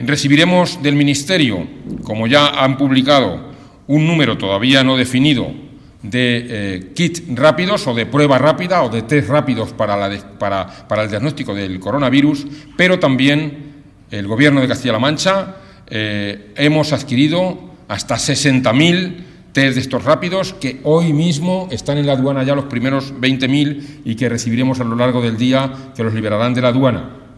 (DIRECTO) Comparecencia informativa videoconferencia mantenida con los gerentes de los hospitales dependientes del SESCAM